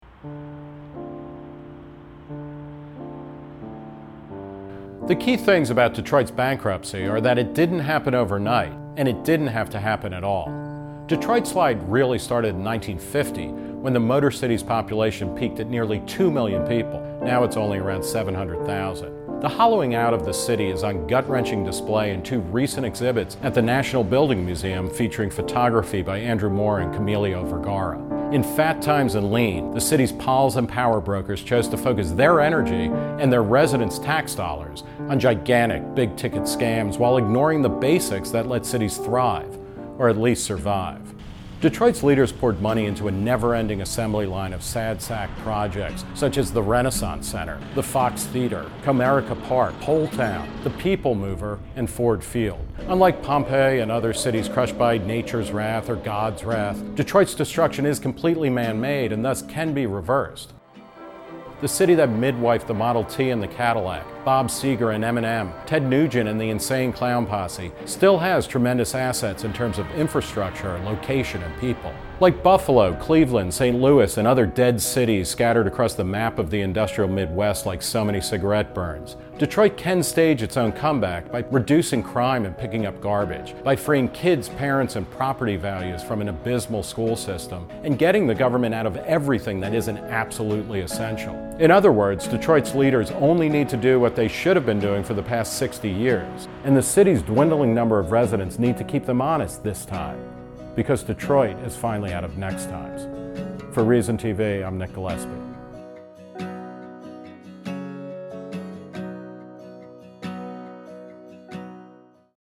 Written and narrated by Nick Gillespie.